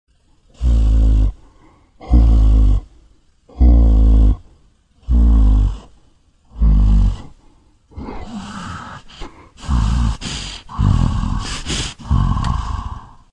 生物的声音 " 生物的大嗅觉 13/14
Tag: 呐喊 怪物 恐怖 僵尸